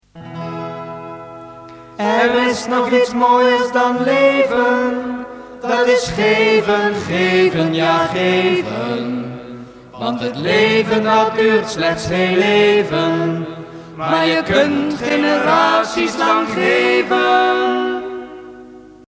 jingle